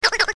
clock08.ogg